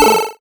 Index of /musicradar/essential-drumkit-samples/Chip Tune Kit
ChipTune Perc 01.wav